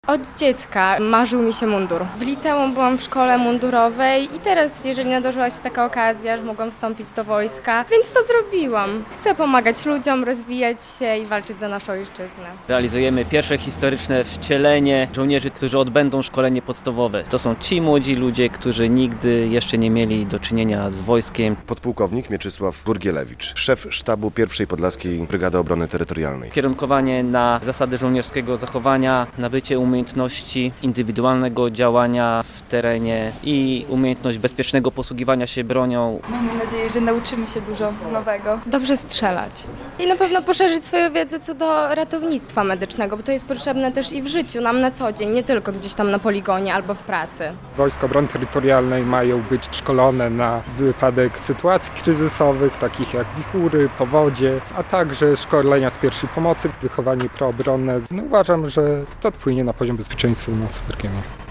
Ponad 50 osób z Pierwszej Podlaskiej Brygady Obrony Terytorialnej rozpoczyna szkolenie - relacja